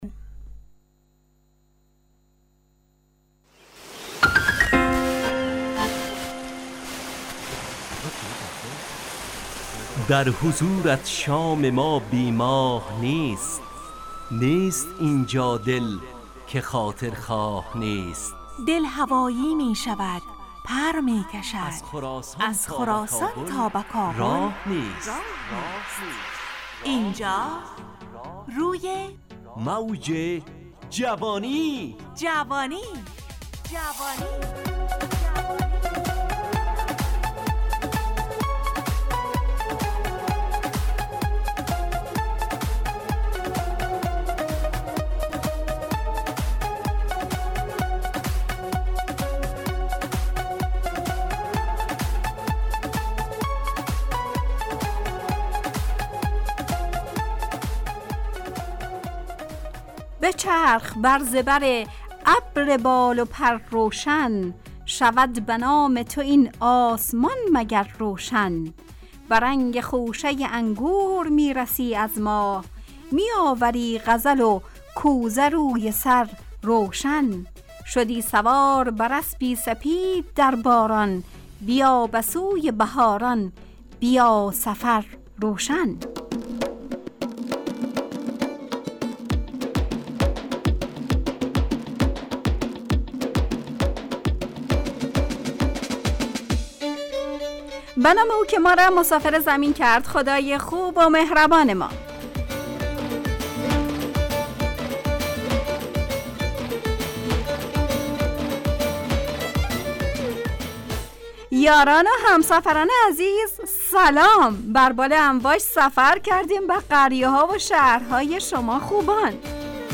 روی موج جوانی، برنامه شادو عصرانه رادیودری.
همراه با ترانه و موسیقی مدت برنامه 70 دقیقه . بحث محوری این هفته (سفر) تهیه کننده